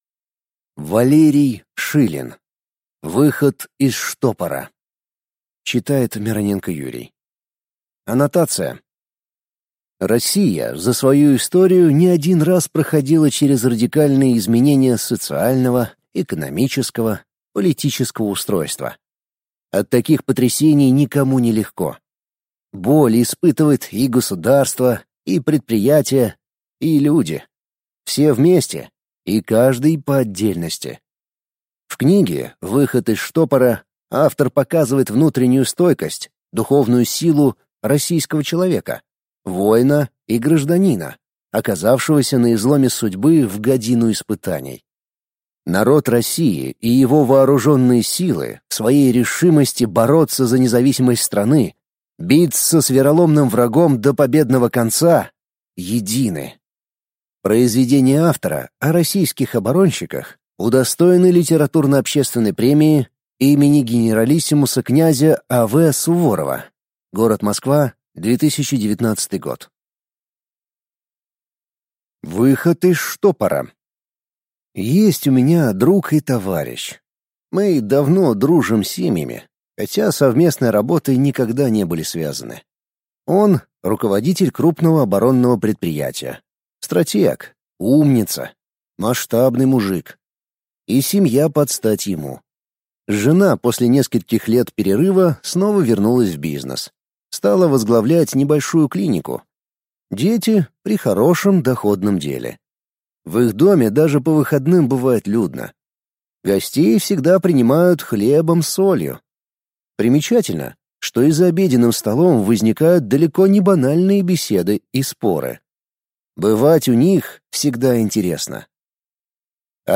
Аудиокнига Выход из штопора | Библиотека аудиокниг